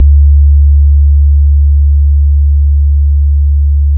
Substantial Bass 65-07.wav